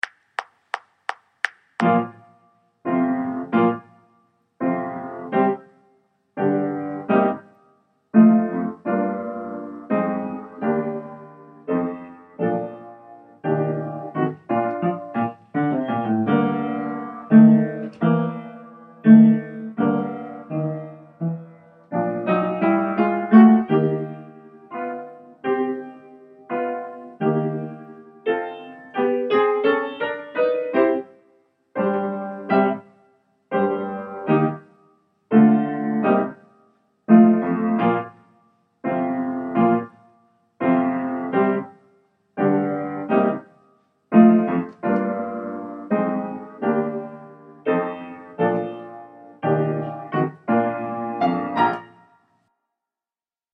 Allegro Con Fuoco Piano Only